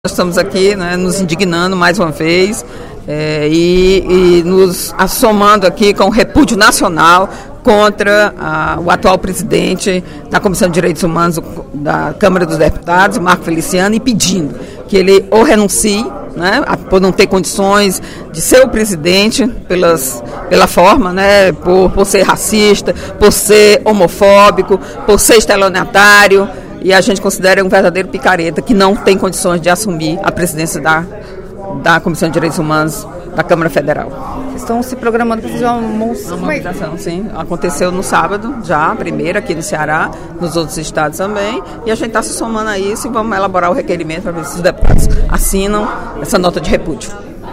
Durante o primeiro expediente da sessão plenária desta terça-feira (12/03), a deputada Eliane Novais (PSB) se posicionou contra a eleição do deputado Marcos Feliciano (PSC-SP) à presidência da Comissão de Direitos Humanos e Minorias da Câmara dos Deputados.